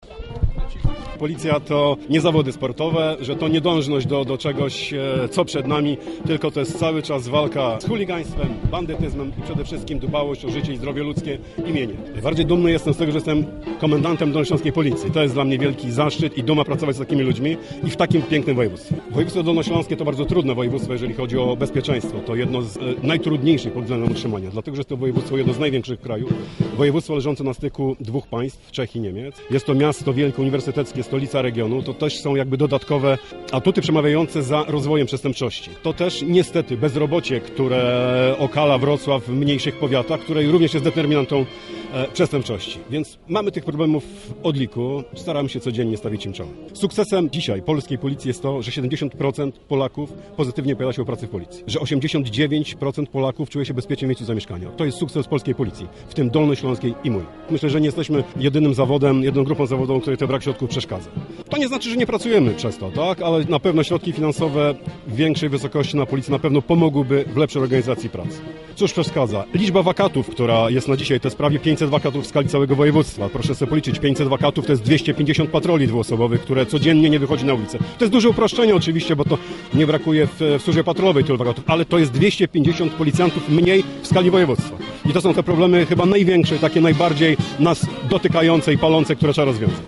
Dolnośląscy policjanci świętują w Rudnej
Inspektor Wojciech Ołdyński, komendant wojewódzki policji we Wrocławiu.